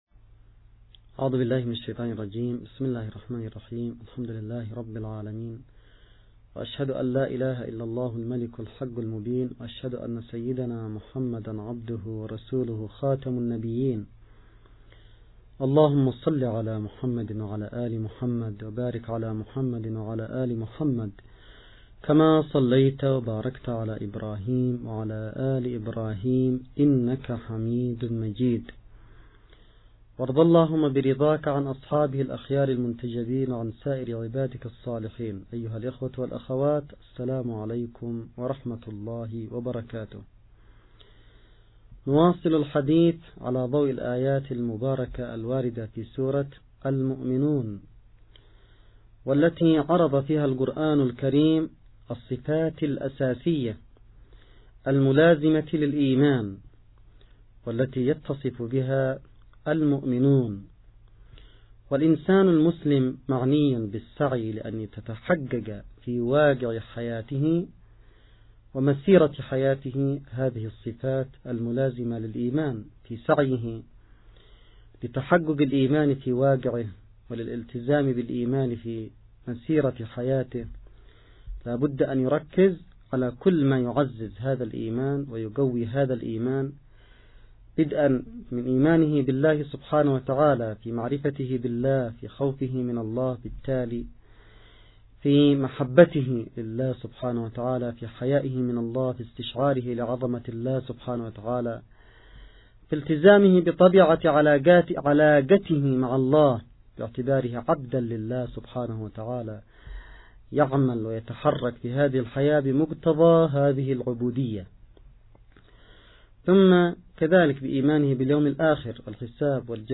إب نيوز23رمضان1439هـ الموافق2018/6/8 :-محاضرة مواصفات المؤمنين 2 – المحاضرة الرمضانية العشرون للسيد عبدالملك بدرالدين الحوثي 1439 هـ .